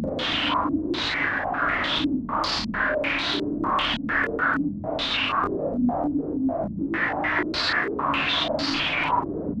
STK_MovingNoiseA-100_01.wav